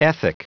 Prononciation du mot ethic en anglais (fichier audio)
Prononciation du mot : ethic